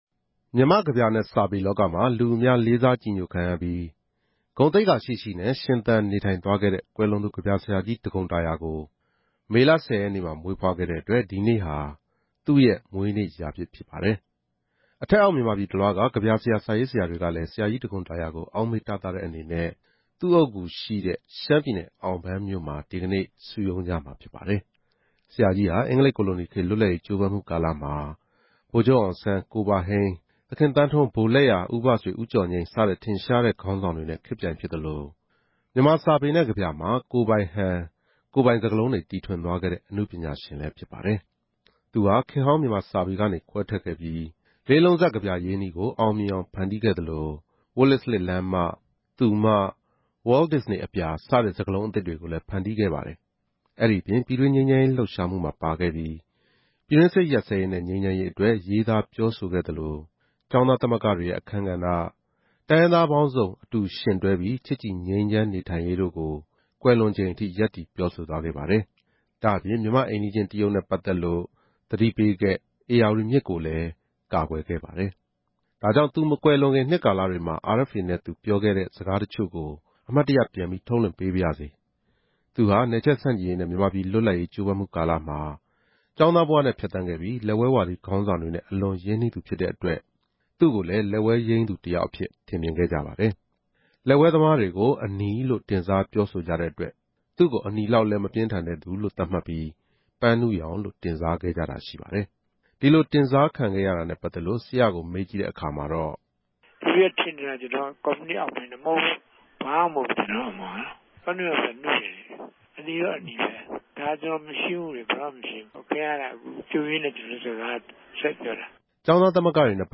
ဒါကြောင့် သူမကွယ်လွန်ခင် နှစ်ကာလတွေမှာ RFA နဲ့ သူပြောခဲ့တဲ့ စကားတချို့ကို အမှတ်တရပြန်ပြီး ထုတ်လွှင့်ပေးပါရစေ။ သူဟာ နယ်ချဲ့ဆန့်ကျင်ရေးနဲ့ မြန်မာပြည် လွတ်လပ်ရေးကြိုးပမ်းမှုကာလမှာ ကျောင်းသားဘဝနဲ့ ဖြတ်သန်းခဲ့ပြီး၊ လက်ဝဲဝါဒီ ခေါင်းဆောင်တွေနဲ့ အလွန်ရင်းနှီးသူ ဖြစ်တဲ့အတွက် သူ့ကိုလည်း လက်ဝဲယိမ်းသူတယောက်အဖြစ် ထင်မြင်ကြပါတယ်။